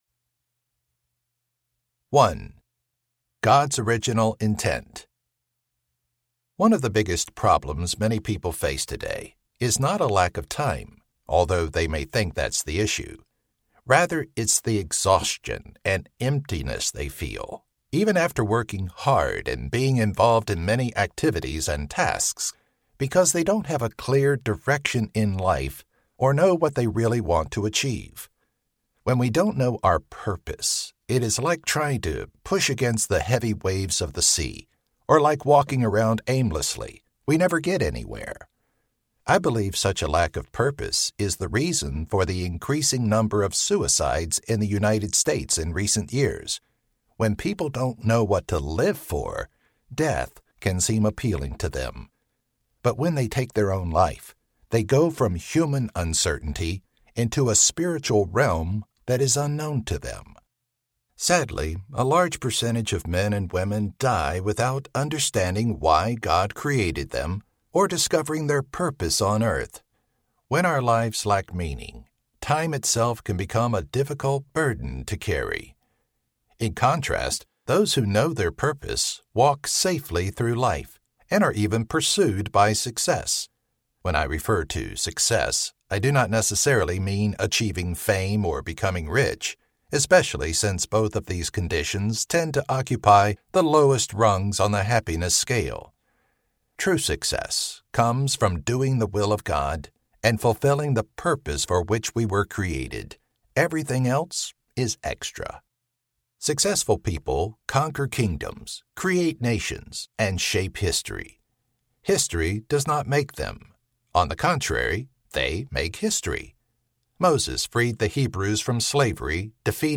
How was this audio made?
5.1 Hrs. – Unabridged